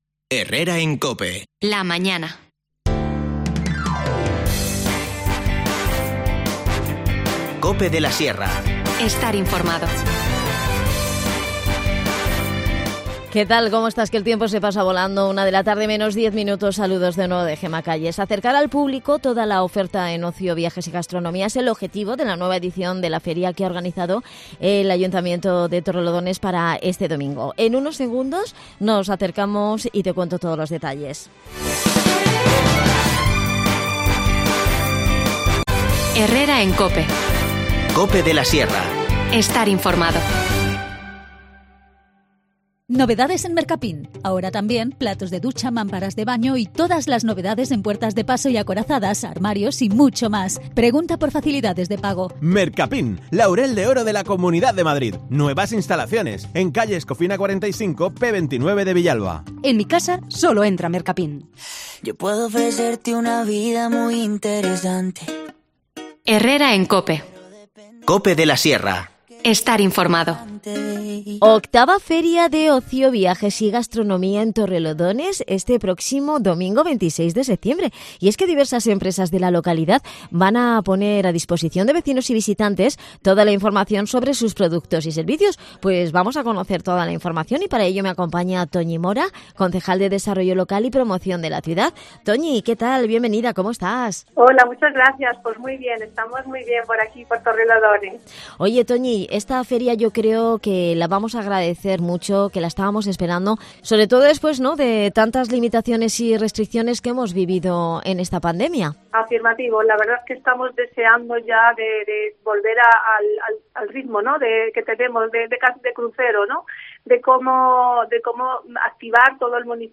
Hablamos en el programa con Toñi Mora, concejal de Desarrollo Local.